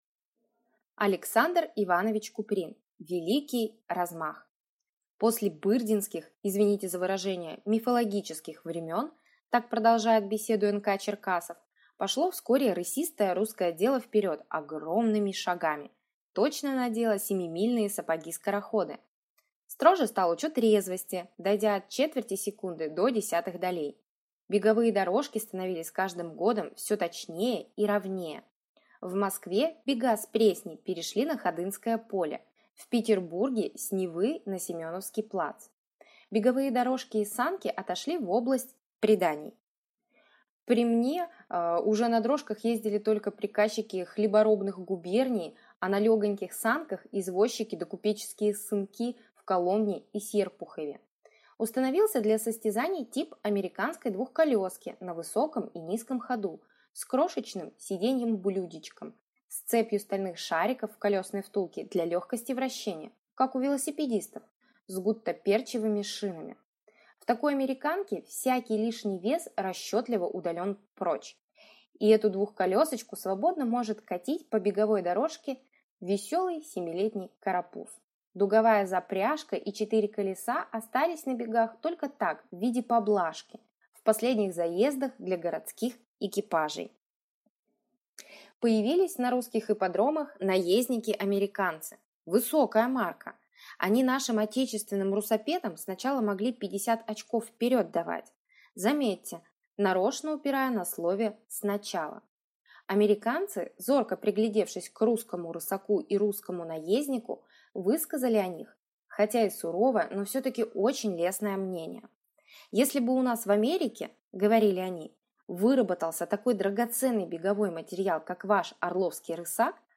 Аудиокнига Великий размах | Библиотека аудиокниг